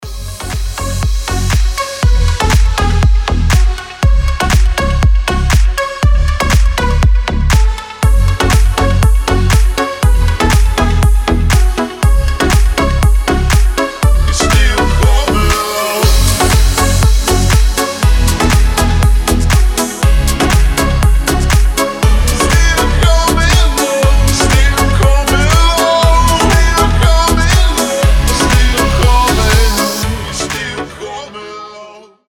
• Качество: 320, Stereo
мужской голос
громкие
deep house